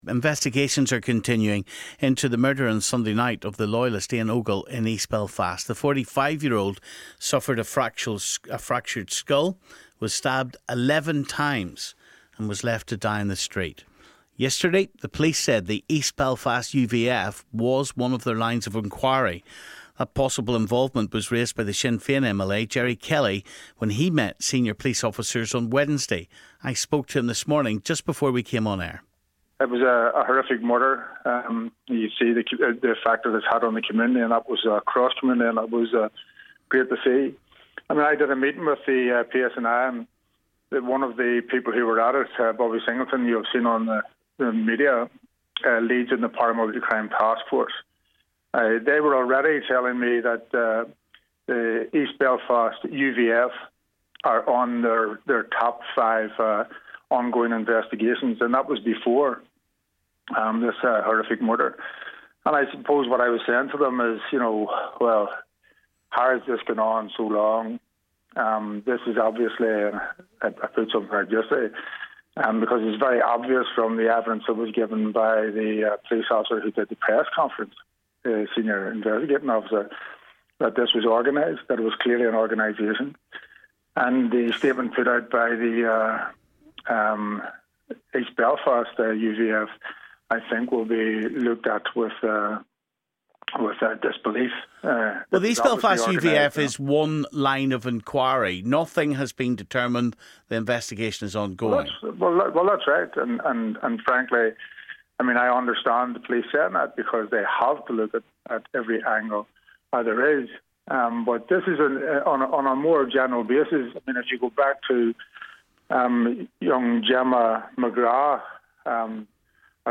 Stephen spoke to Sinn Fein MLA Gerry Kelly and loyalist blogger